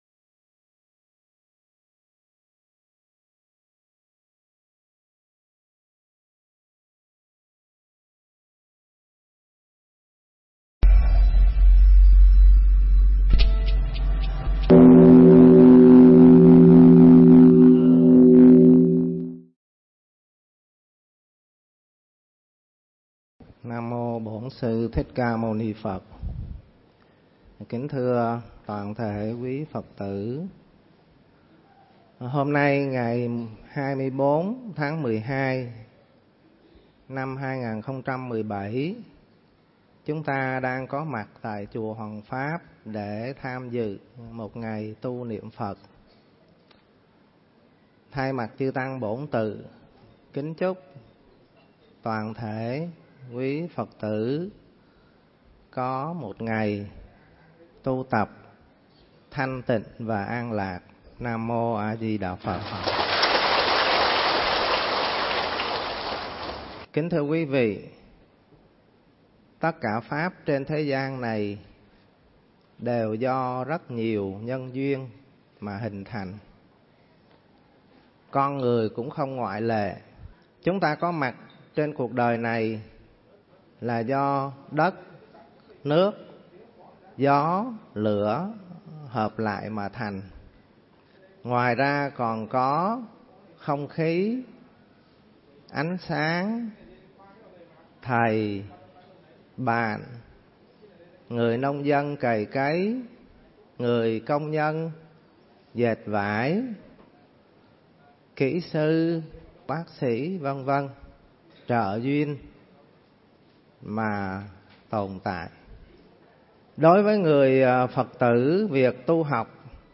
Mp3 Pháp Thoại 10 Pháp Hộ Trì
trong khóa tu Niệm Phật Một Ngày tại chùa Hoằng Pháp (Hóc Môn, HCM)